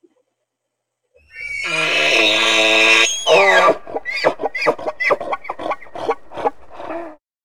Звуки марала
Голос благородного оленя в осеннем лесу